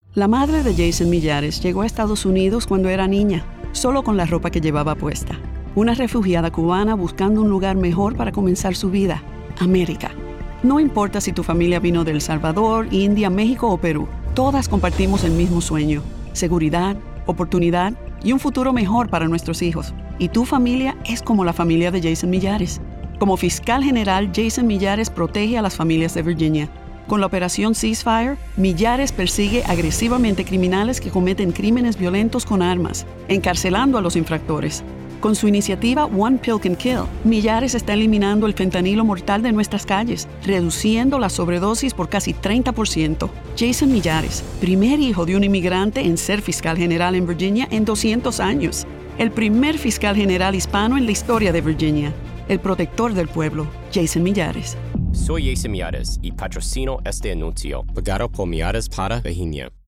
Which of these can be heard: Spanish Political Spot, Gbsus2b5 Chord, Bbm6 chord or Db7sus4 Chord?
Spanish Political Spot